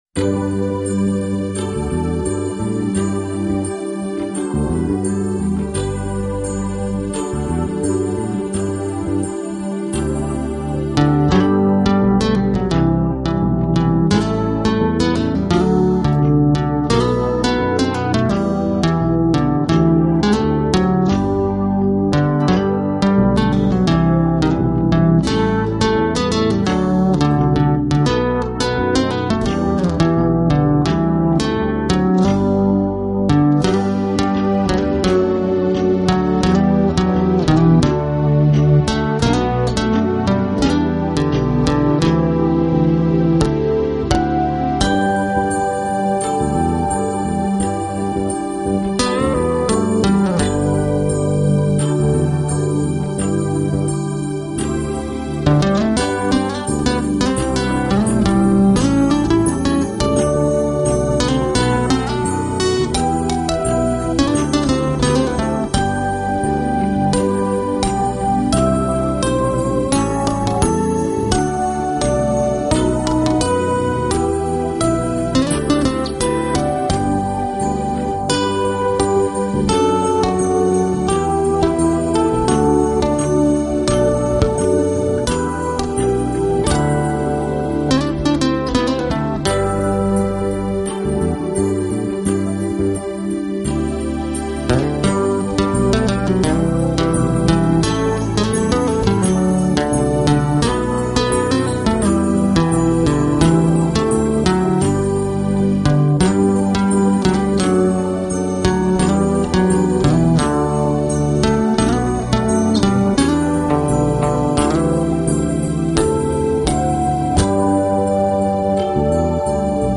爵士吉它